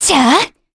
Valance-Vox_Casting1_kr.wav